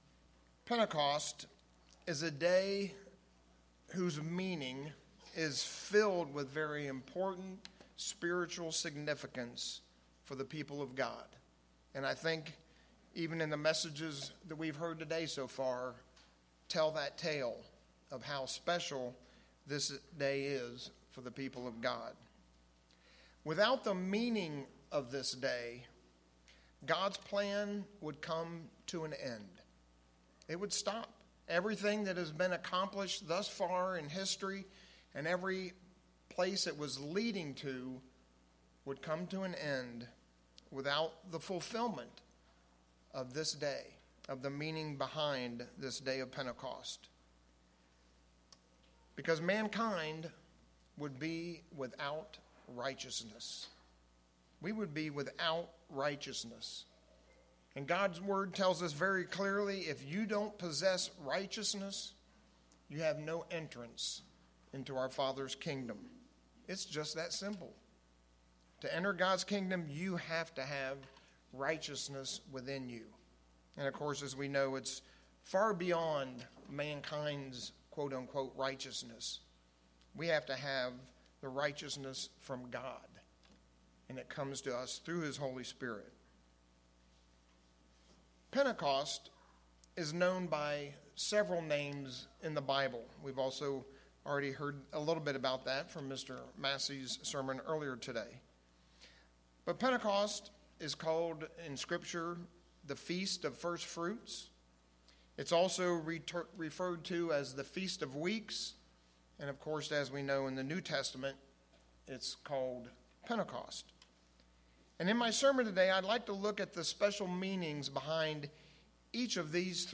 All three point to the significance, and meaning for this day. In our sermon today, we'll look at these three titles, and see God's special message for His people.